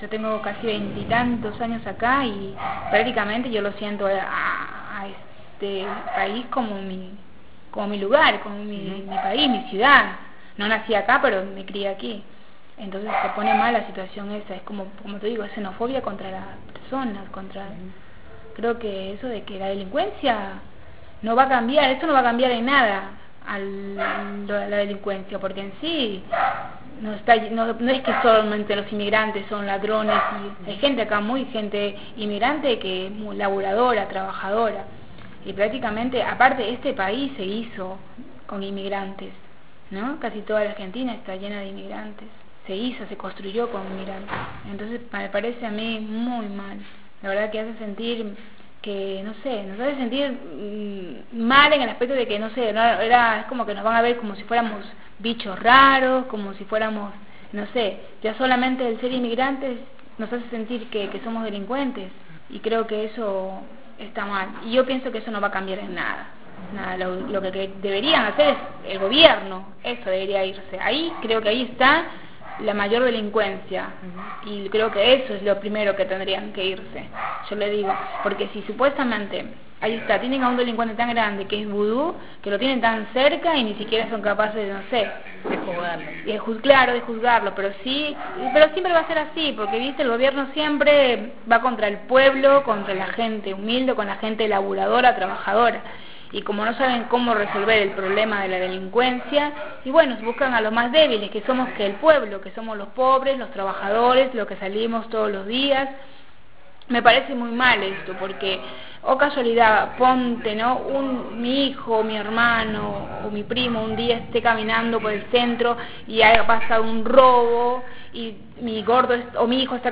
Las voces que podés escuchar más abajo son de mujeres y jóvenes migrantes del Barrio JL Cabezas de La Plata, uno de los más castigados por la inundación del año pasado.